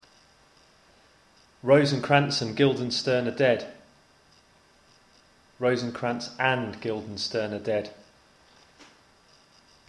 The pitch rises and you say it more loudly. The sounds change too: when unstressed and comes out as [ən], with a pretty indistinct vowel; when stressed, it comes out as [æn] or [ænd]: the vowel becomes more distinctive.